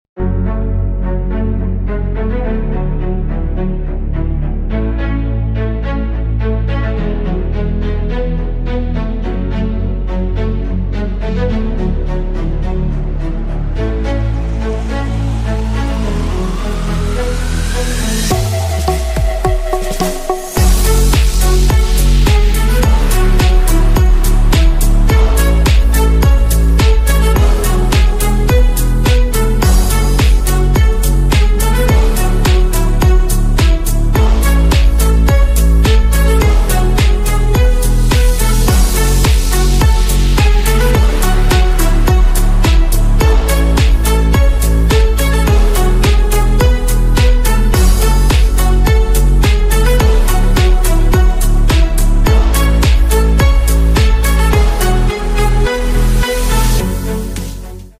O PVC Pipe Extrusion Production Line, sound effects free download